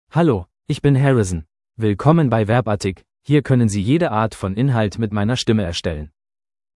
MaleGerman (Germany)
HarrisonMale German AI voice
Harrison is a male AI voice for German (Germany).
Voice sample
Listen to Harrison's male German voice.
Harrison delivers clear pronunciation with authentic Germany German intonation, making your content sound professionally produced.